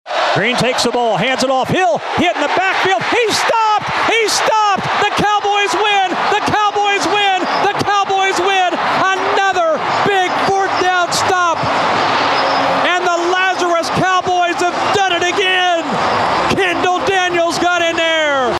OK State Final Call 9-9.mp3